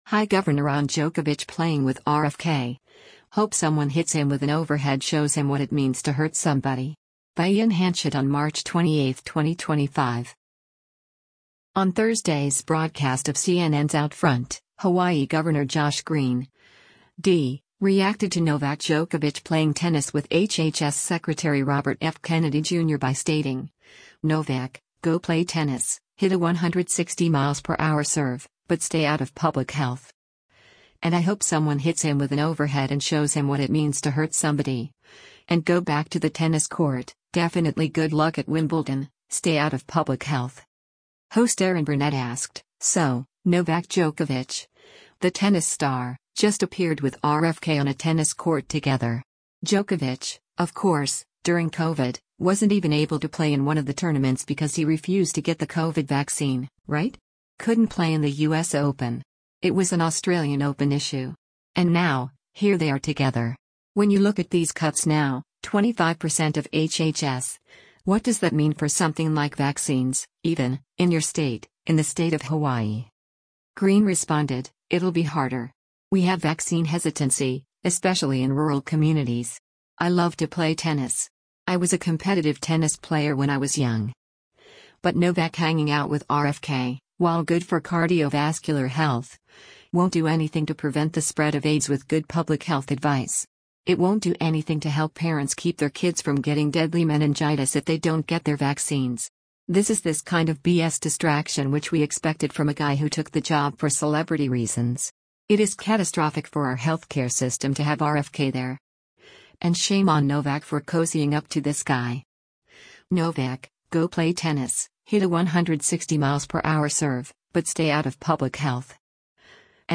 On Thursday’s broadcast of CNN’s “OutFront,” Hawaii Gov. Josh Green (D) reacted to Novak Djokovic playing tennis with HHS Secretary Robert F. Kennedy Jr. by stating, “Novak, go play tennis, hit a 160 mph serve, but stay out of public health.